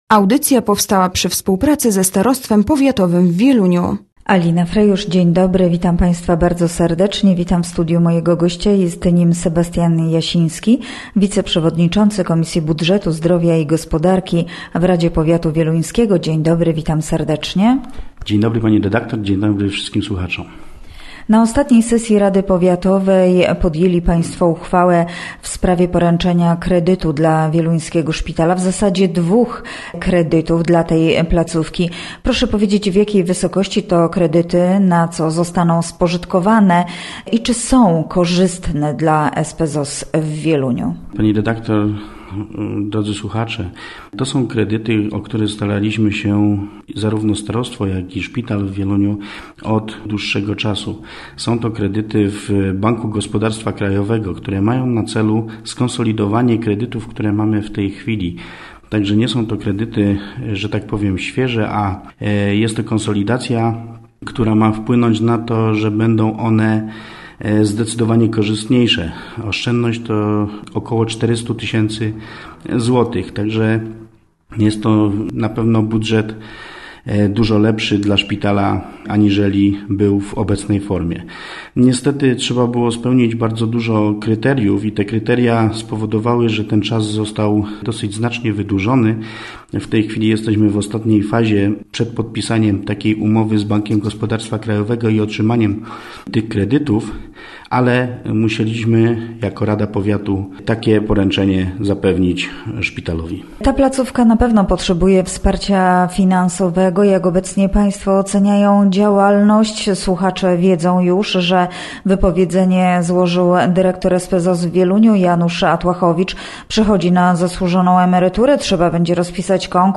Gościem Radia ZW był Sebastian Jasiński, radny powiatowy